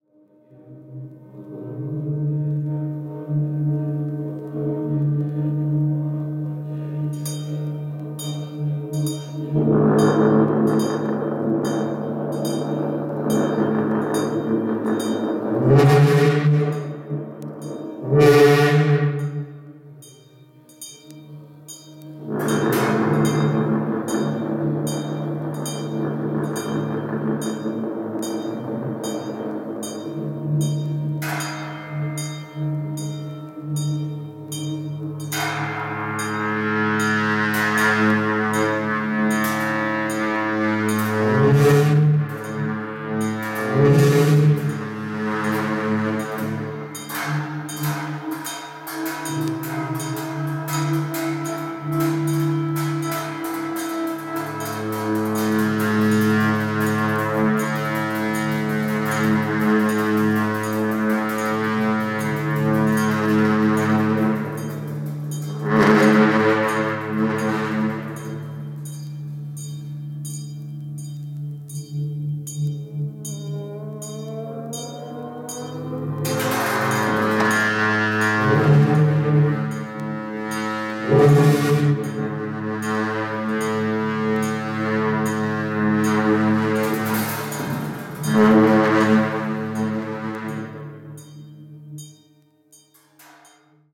Title : Tibetan Buddhism: The Ritual Orchestra And Chants
チベット幻覚系・フィールド録音盤！！！